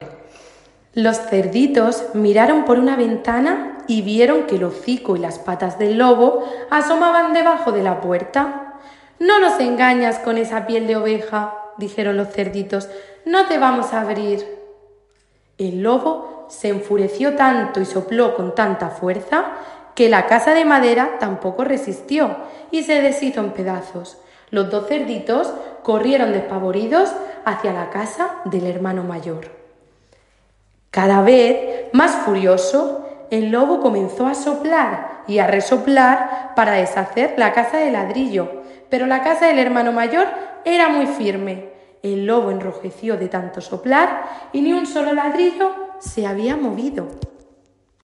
Fragmento de cuento narrado